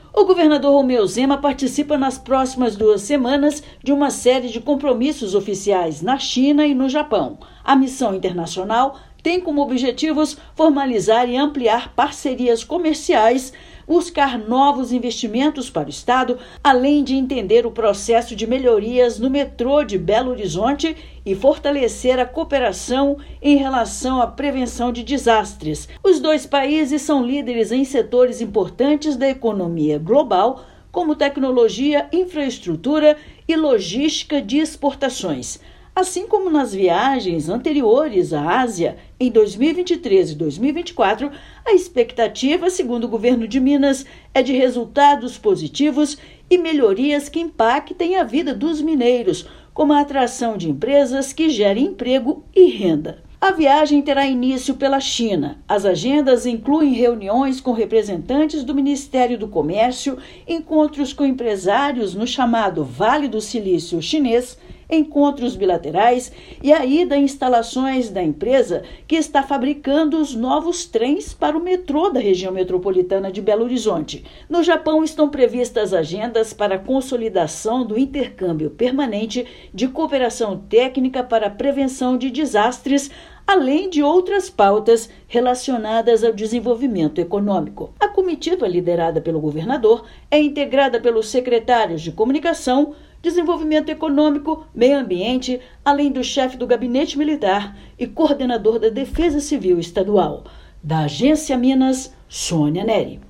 Comitiva do Estado terá uma série de compromissos oficiais na China e no Japão a partir da próxima segunda-feira (16/6). Ouça matéria de rádio.